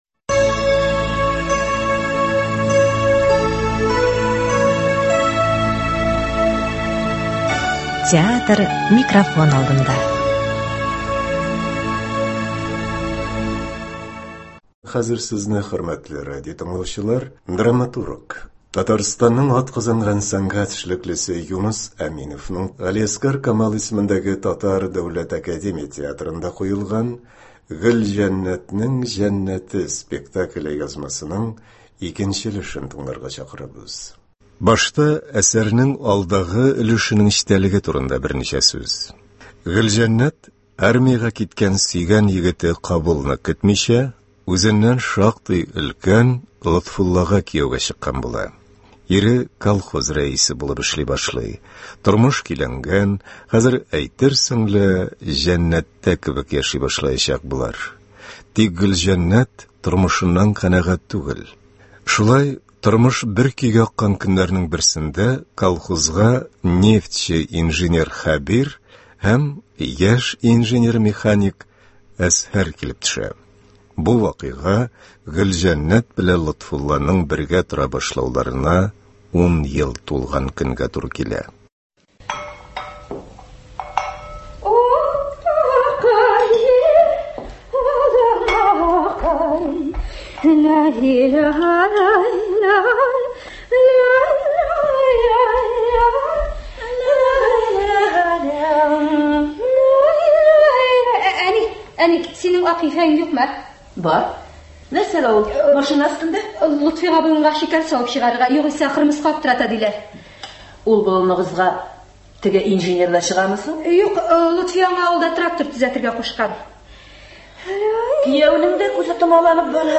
Театр - микрофон алдында (11.08.24) | Вести Татарстан
Бүген без сезнең игътибарга драматург, Татарстанның атказанган сәнгать эшлеклесе Юныс Әминевнең Г.Камал исемендәге Татар Дәүләт академия театрында куелган “Гөлҗәннәтнең җәннәте” спектакле язмасын тәкъдим итәбез.